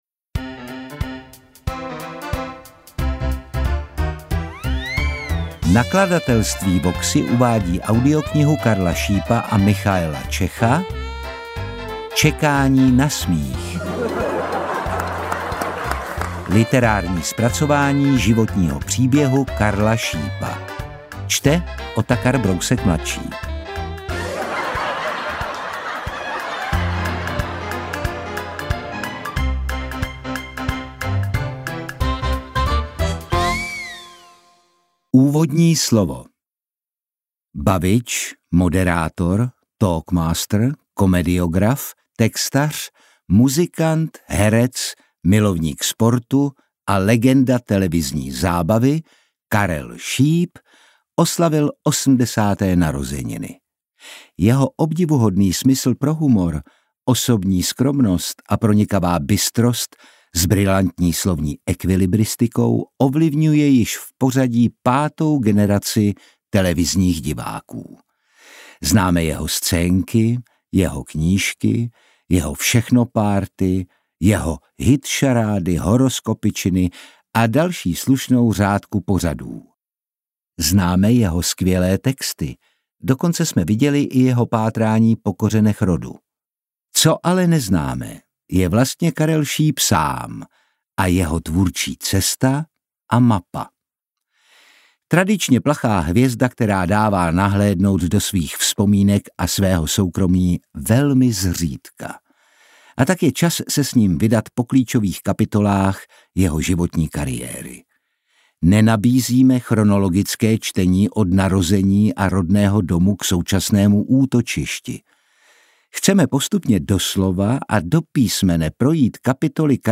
Interpret:  Otakar Brousek
AudioKniha ke stažení, 12 x mp3, délka 4 hod. 34 min., velikost 250,6 MB, česky